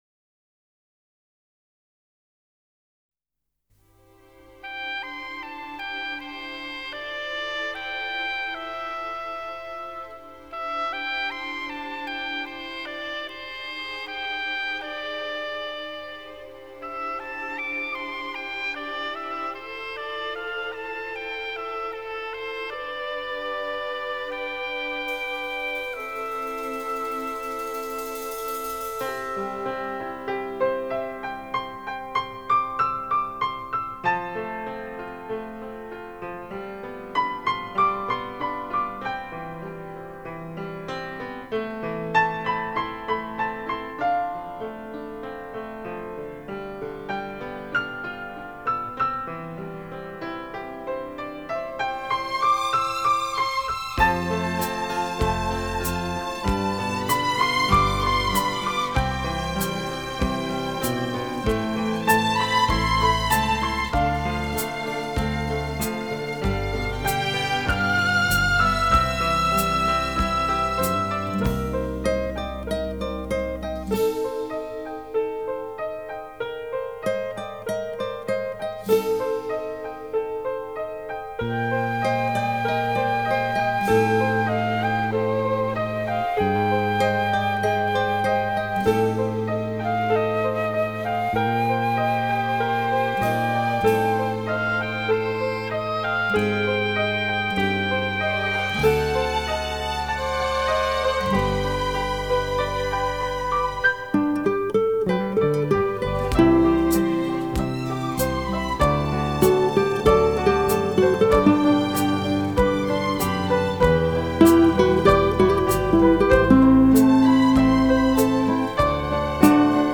里面收录的曲目大都是在过去几十年间在欧美流行的歌曲改编的器乐曲，